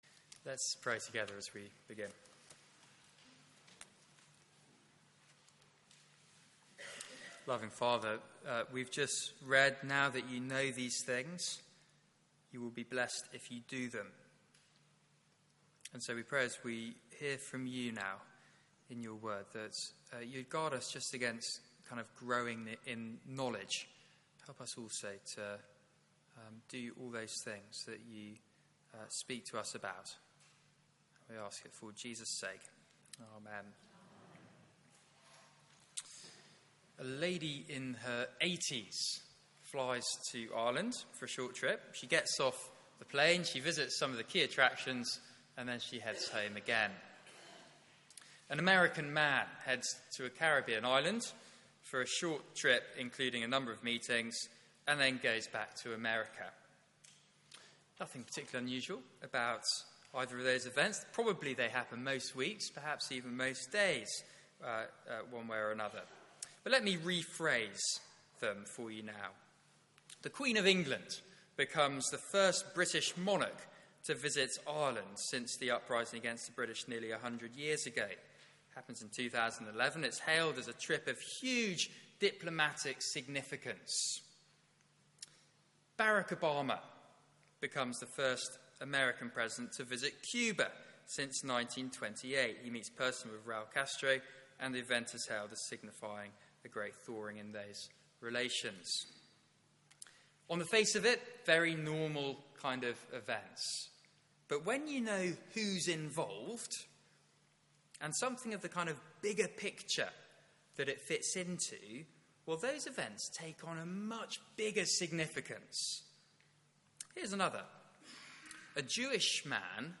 Media for 9:15am Service on Sun 15th May 2016 09:15 Speaker
Service Sermon (11:00 Service)